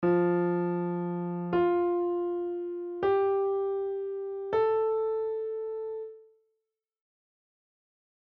I'm going to play F then up an octave and F
then 2 white notes of my choice